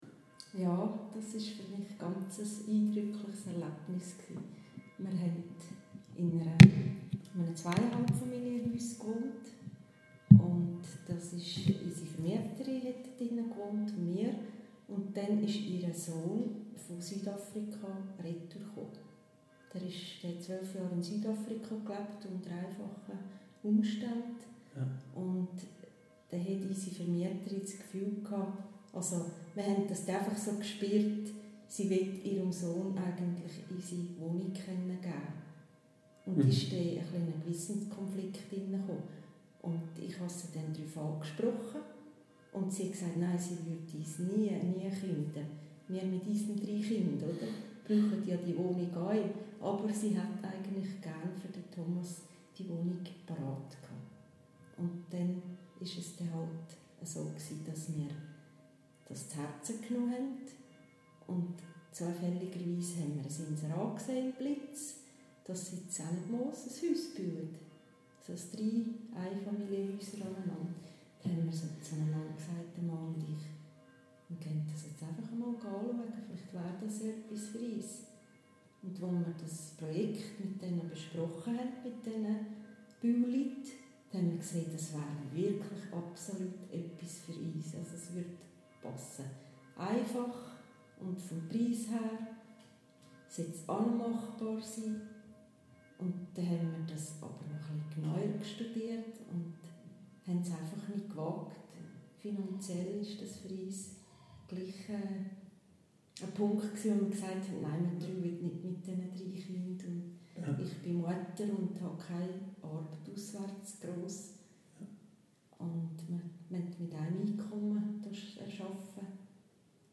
Hierzu können Sie hier Zeugnisse von Ennetmooser Landsleuten über das Eingreifen Gottes in Ihr Leben hören:
Kirche-Ennetmoos-Interview-01.mp3